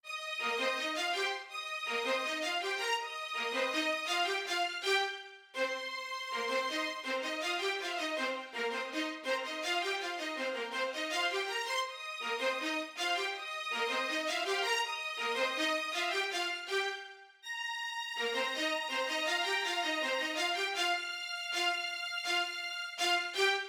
11 strings 2 A.wav